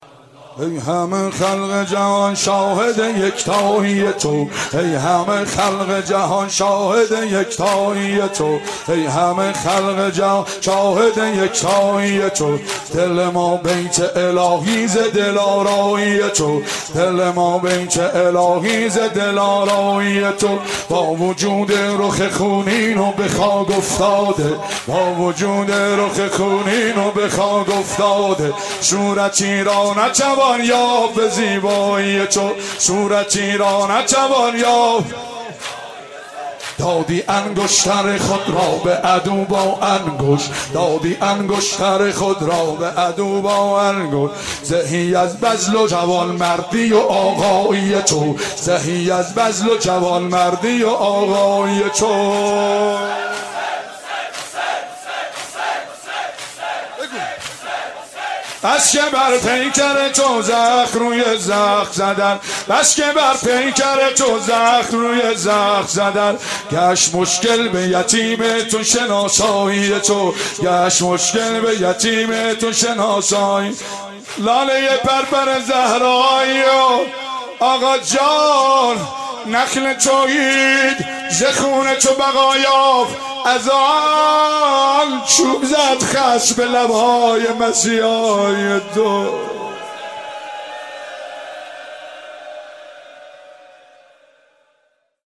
روضه حضرت امام حسین علیه السلام (روضه)